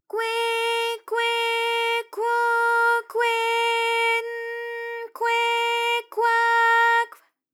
ALYS-DB-001-JPN - First Japanese UTAU vocal library of ALYS.
kwe_kwe_kwo_kwe_n_kwe_kwa_kw.wav